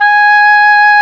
WND OBOE3 07.wav